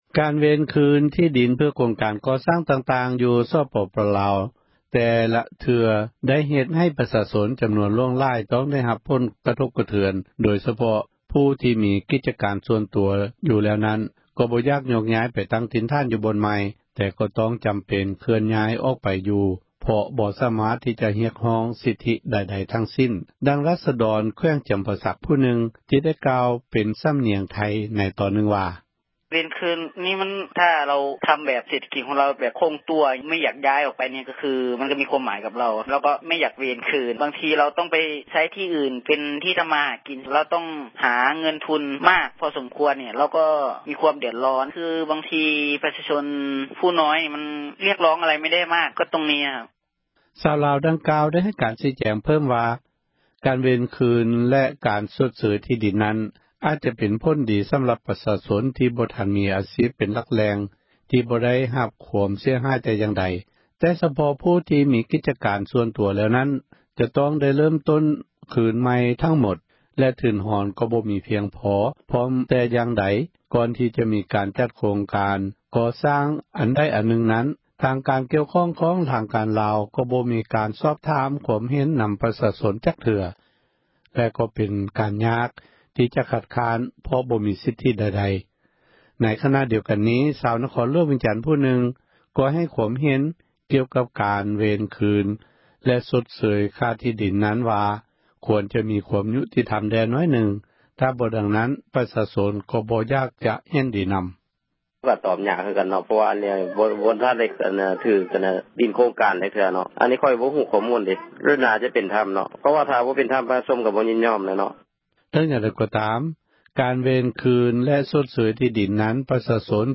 ກາຣເວນຄືນ ທີ່ດິນ ເພື່ອ ໂຄງກາຣ ກໍໍ່ສ້າງ ຕ່າງໆໃນ ສປປລາວ ແຕ່ລະເທື່ອ ໄດ້ເຮັດໃຫ້ ປະຊາຊົນ ຈຳນວນ ຫລວງຫລາຍ ຕ້ອງໄດ້ຮັບ ຄວາມ ເດືອນຮ້ອນ ໂດຽສະເພາະ ຜູ້ທີ່ມີ ກິຈກາຣ ສ່ວນຕົວ ຢູ່ແລ້ວ ນັ້ນ ກໍບໍ່ຢາກ ໂຍກຍ້າຍ ໄປຕັ້ງຖິ່ນຖານ ຢູ່ບ່ອນໃໝ່ ແຕ່ກໍຕ້ອງ ຈຳເປັນຍ້າຍ ໄປຢູ່ ບ່ອນໃໝ່ ເພາະ ບໍ່ສາມາຕ ທີ່ຈະຮຽກຮ້ອງ ສິທທິໃດໆ ທັ້ງສິ້ນ. ດັ່ງທີ່ ຣາສດອນ ແຂວງ ຈຳປາສັກ ຜູ້ໜຶ່ງ ໄດ້ເວົ້າ ເປັນສຳນຽງ ໄທຽ ວ່າ: